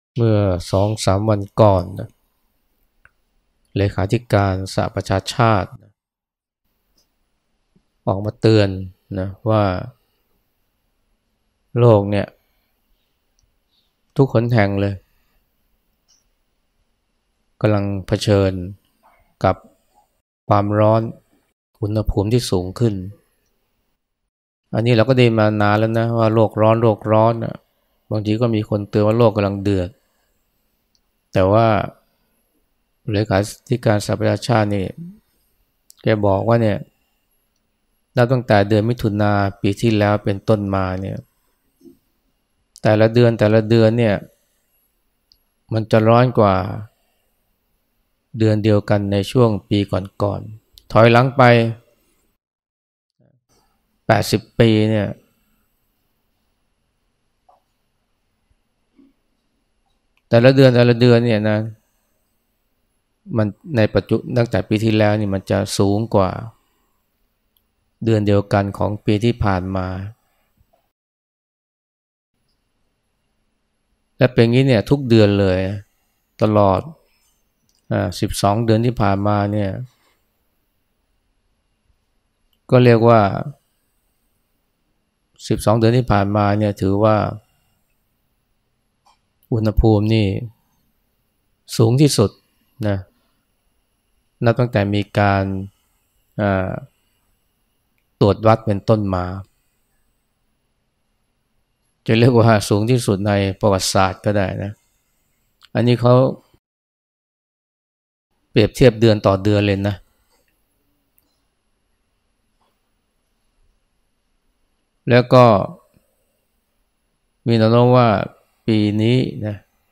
พระอาจารย์ไพศาล วิสาโล วัดป่าสุคะโต แสดงธรรมก่อนฉันเช้าวันที่ 27 กรกฎาคม 2567 เมื่อ 2-3 วันก่อน เลขาธิการสหประชาชาติออกมาเตือนว่า ทุกหนแห่งในโลกกำลังเผชิญกับคว ...